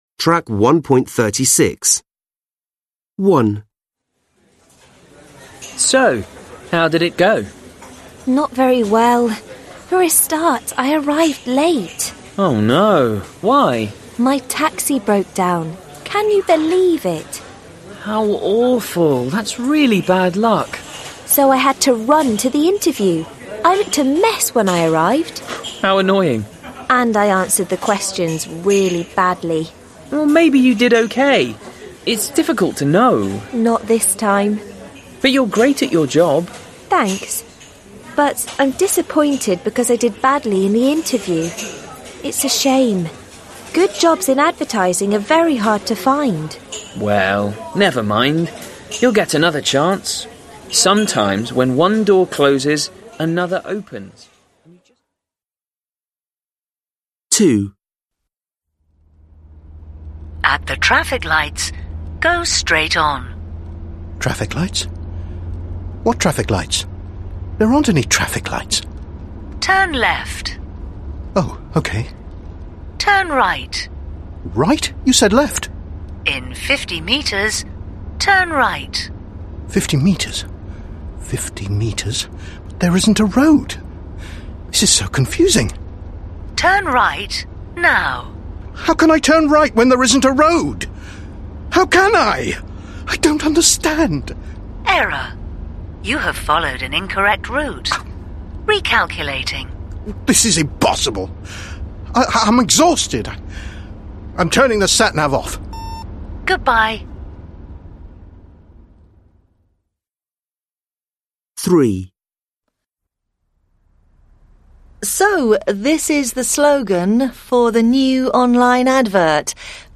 1. an advertising executive in a business meeting.